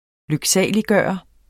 Udtale [ løgˈsæˀliˌgɶˀʌ ]